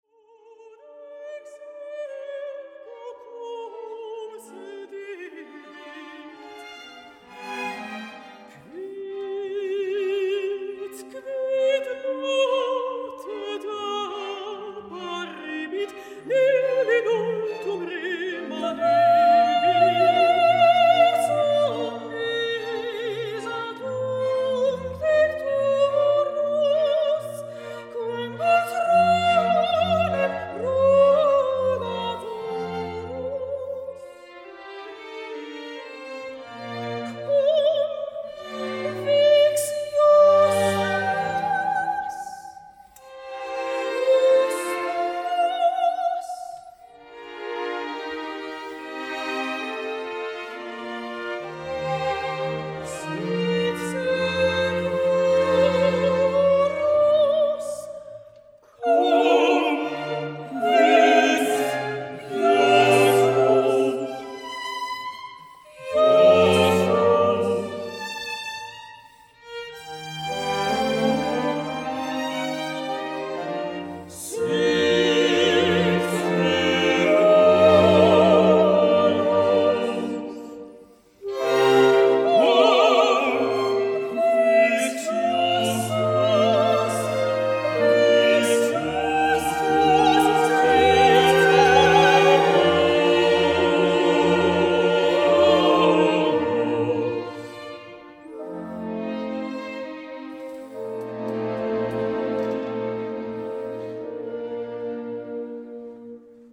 Ein grossartiges Werk mit Chor, Solisten & Orchester.